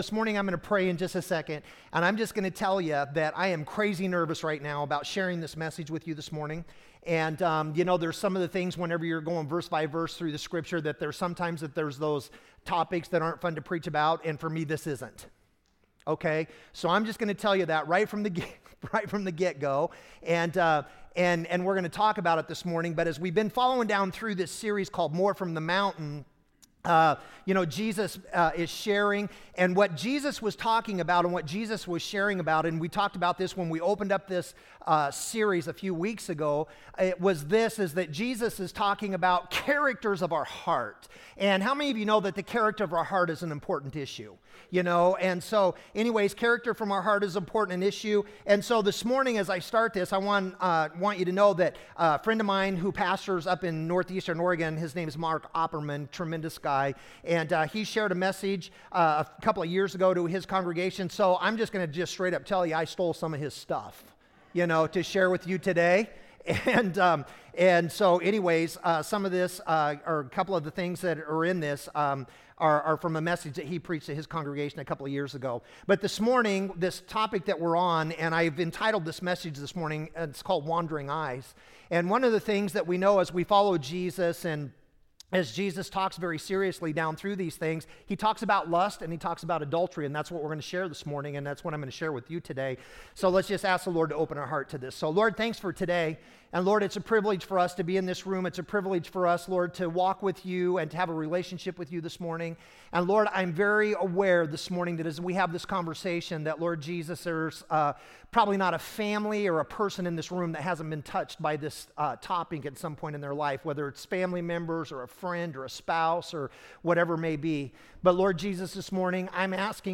Sermons - Redmond Assembly of God